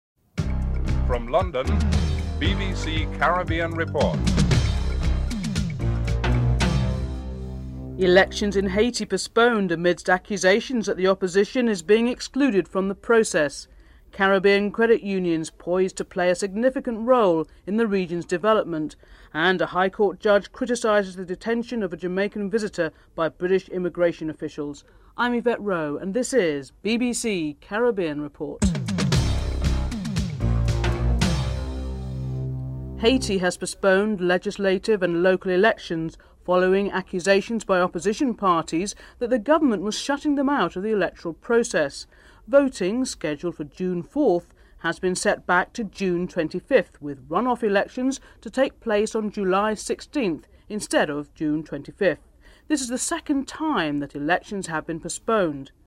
4. Britain's Sunday Times newspaper reports that the humble banana is on the brink of causing a trade war between the US and Britain (06:05-07:15)